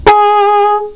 flugelh1.mp3